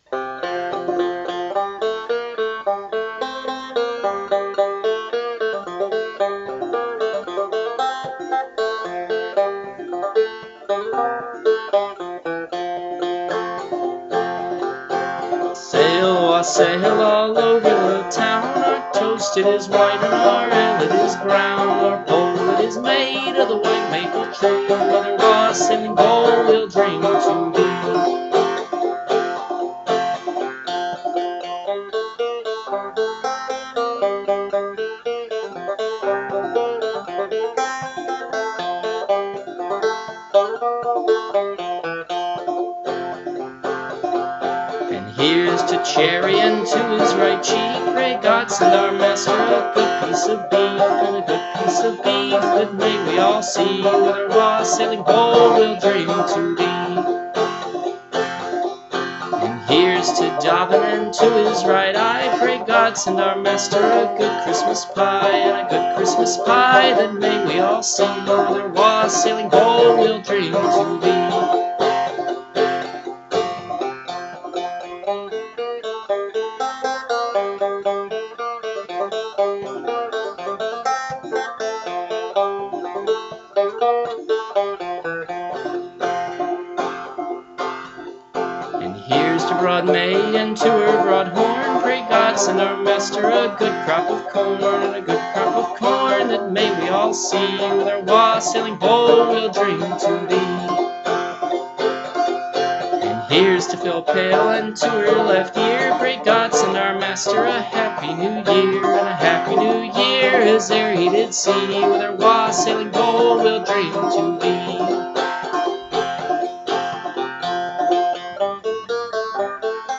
banjo and voice
Words and music: English traditional
This wassailing song has a very catchy tune which is a lot of fun to play on the banjo. I recorded this in the key of F.